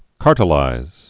(kärtə-līz)